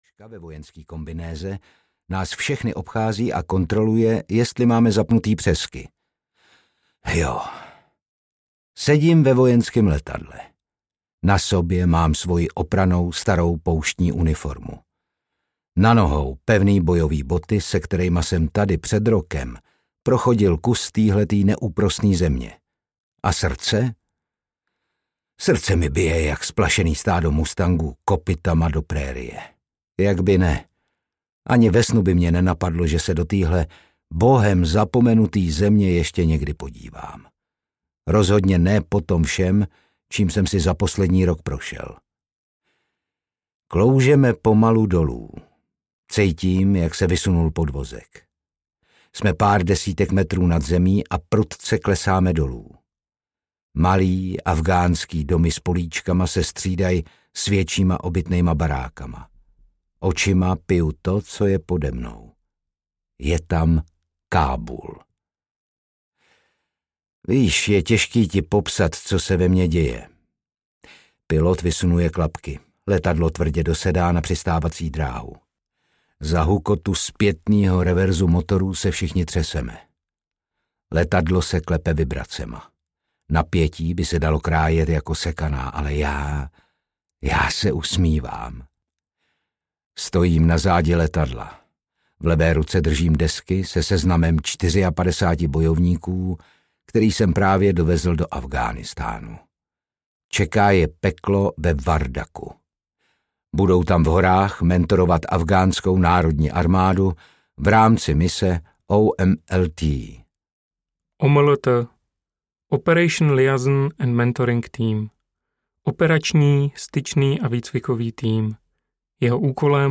Ukázka z knihy
Jde v prvé řadě zejména o příběh člověka!Audiokniha přednesená podmanivým hlasem herce Otakara Brouska ml. zachycuje růst mladého muže od postpubertálního zrání až po dospělého vojáka, jenž musel projít obtížnou řadou profesních, ale i životních zkoušek.